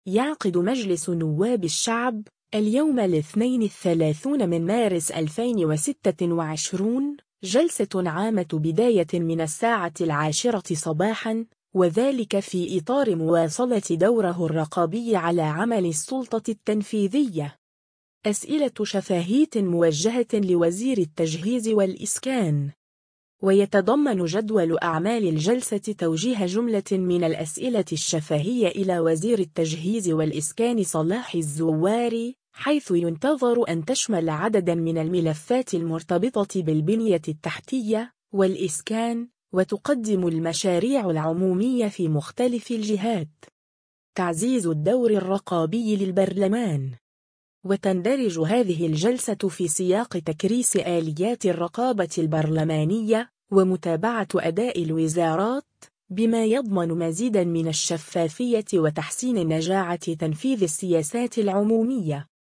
يعقد مجلس نواب الشعب، اليوم الاثنين 30 مارس 2026، جلسة عامة بداية من الساعة العاشرة صباحًا، وذلك في إطار مواصلة دوره الرقابي على عمل السلطة التنفيذية.
أسئلة شفاهية موجهة لوزير التجهيز والإسكان